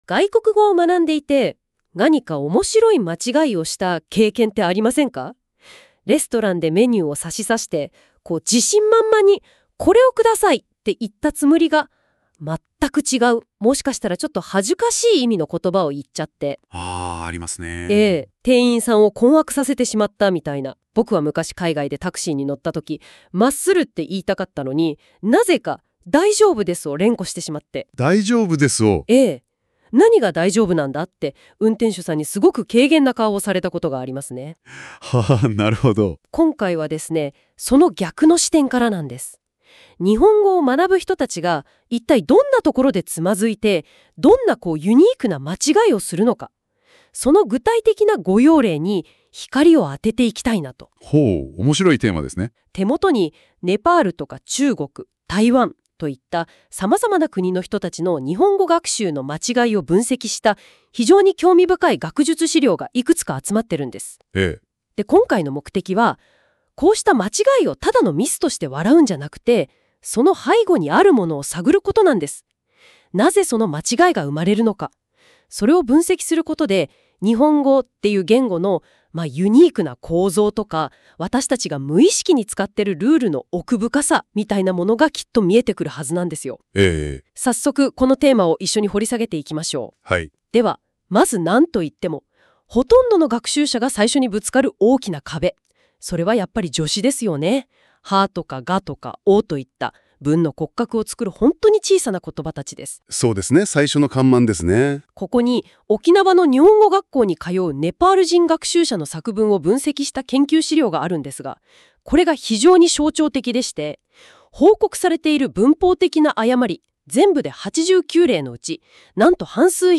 結果 画像化 プレゼン用ファイル的なもの The_Cognitive_Science_of_Error 自動生成されたレポート 日本語学習者における格助詞および文法要素の習得過程と誤用分析：ブリーフィング文書 音声ファイル 生成されたファイル ところどころつまんで、それっぽいこと感想を言い合うみたいなノリは変わらず。